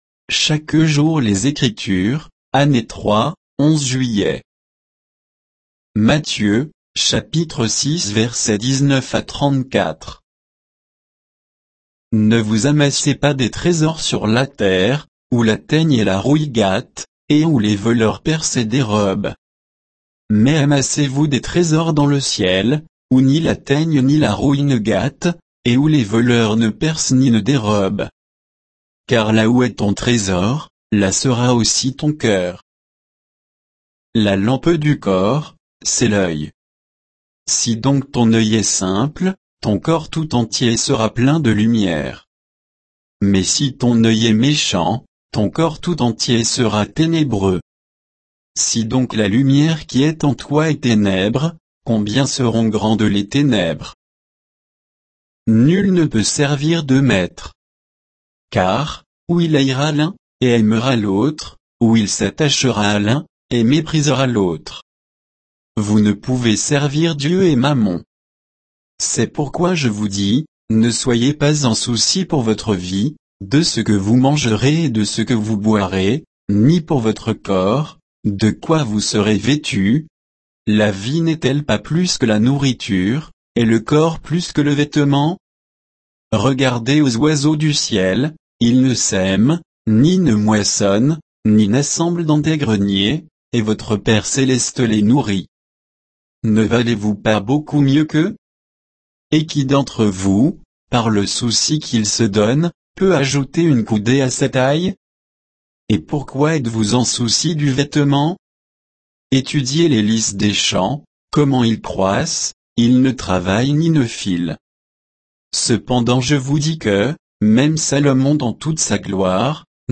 Méditation quoditienne de Chaque jour les Écritures sur Matthieu 6